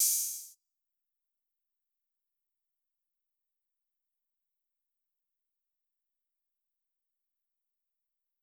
Open Hat (2).wav